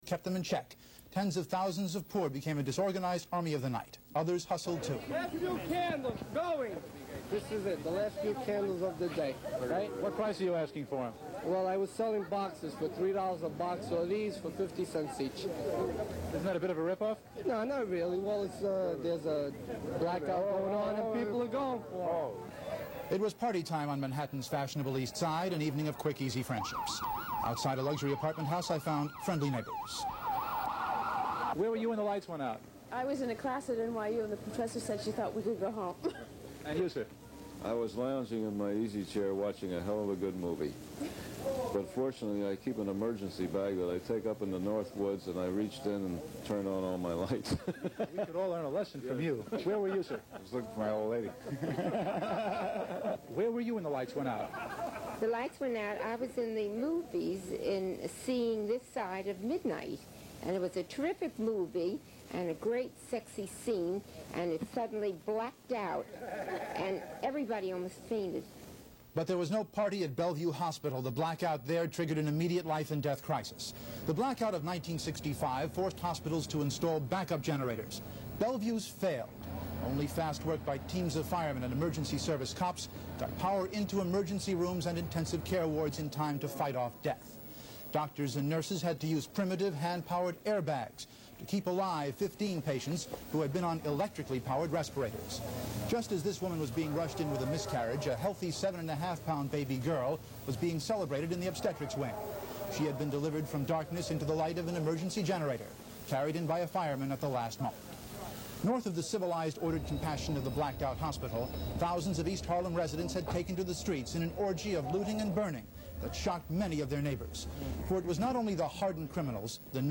News coverage of the NYC sound effects free download